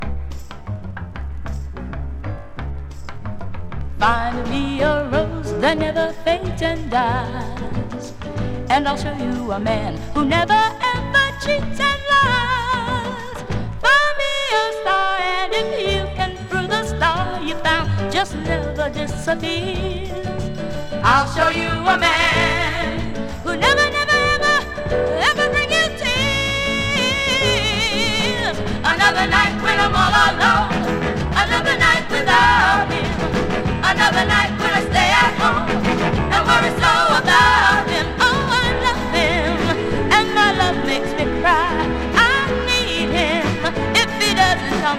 Pop, Soul, Vocal　USA　12inchレコード　33rpm　Stereo